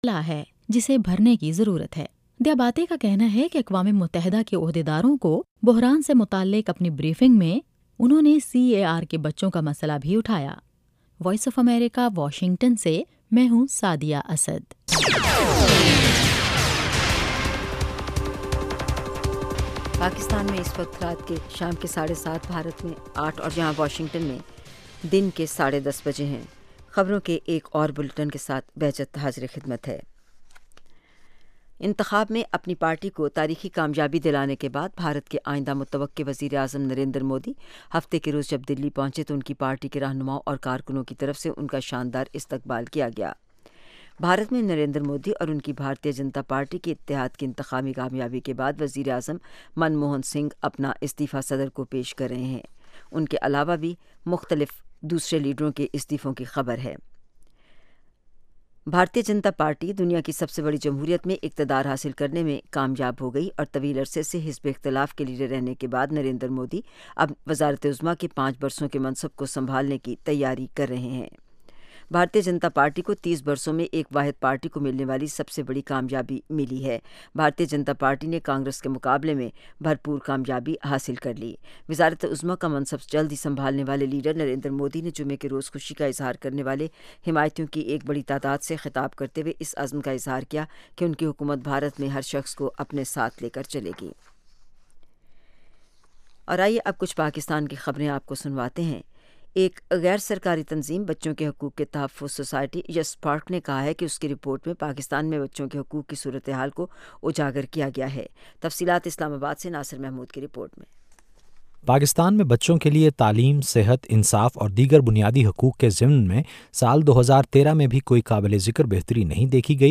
Urdu Headlines